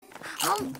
Play, download and share Satania Chips 1 original sound button!!!!
gabriel-dropout-satania-noises-2-mp3cut_w6icbzE.mp3